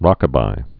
(rŏkə-bī)